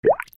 スポイト